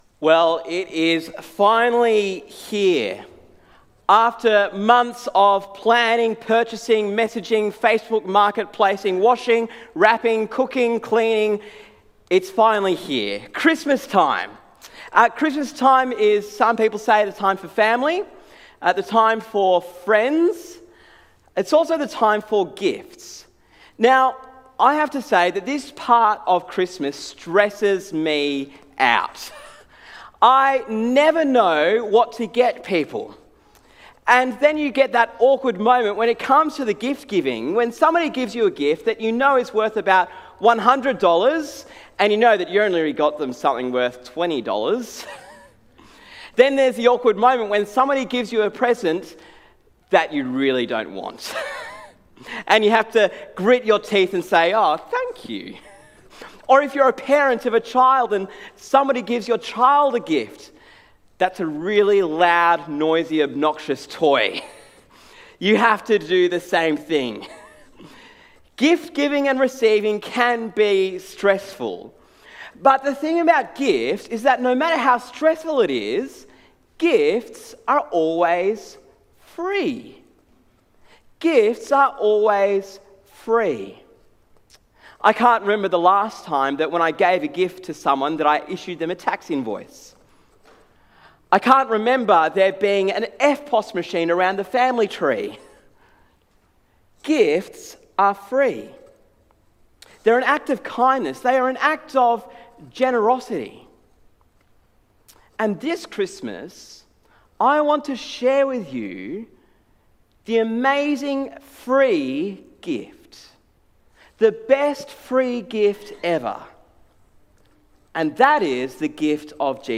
Sermon on Matthew 1 - Christmas 2025